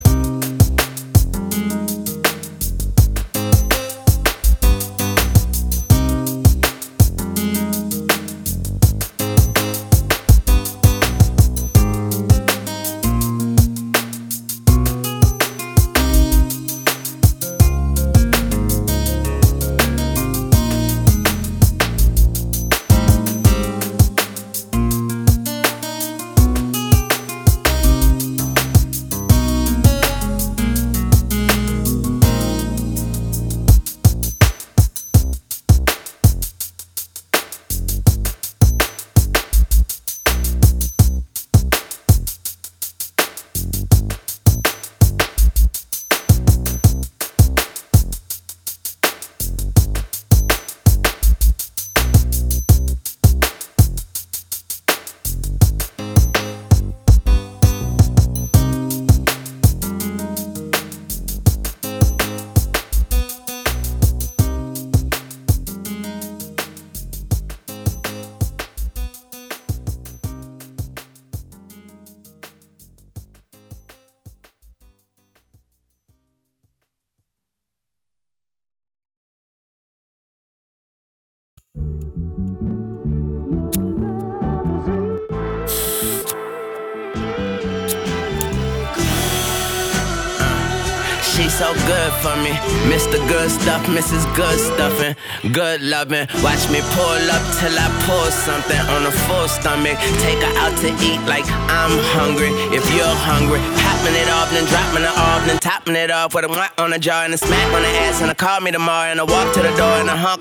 Hiphop
Description : OHIO Hip-Hop Muzik!!!